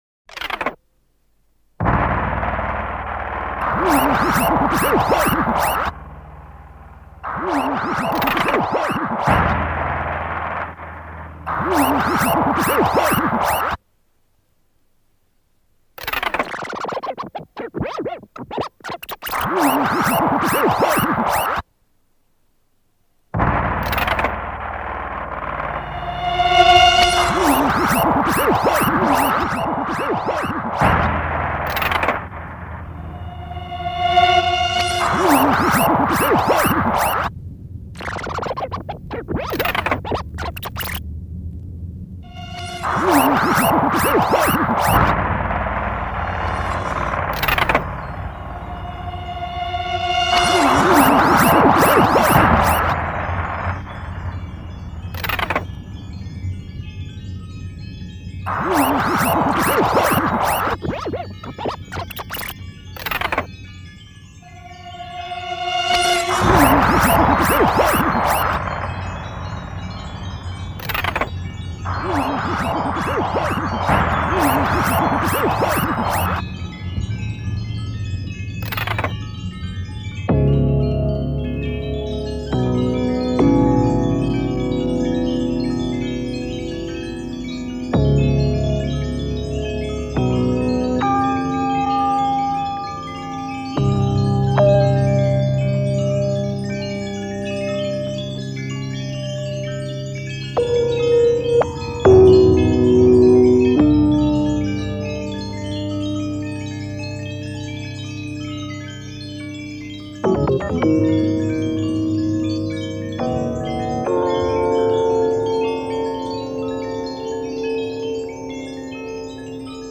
early tape music (1975-77)